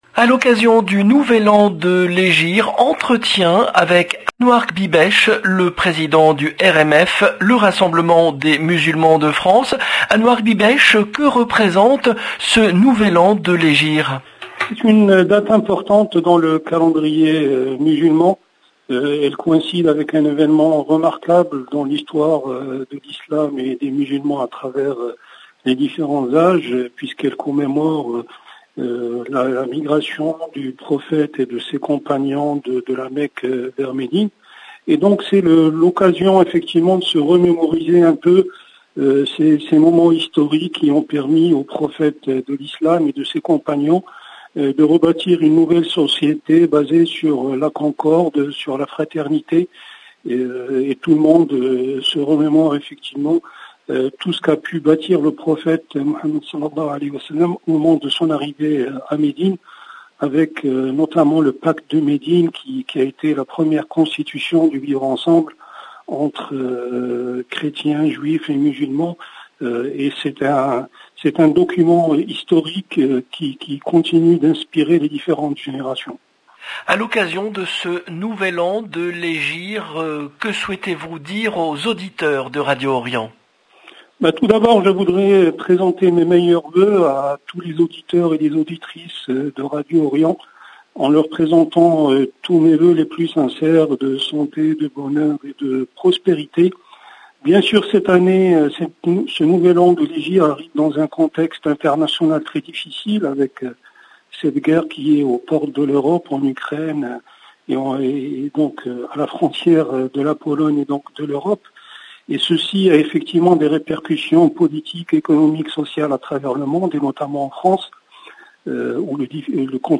RENCONTRE, Samedi 30 juillet 2022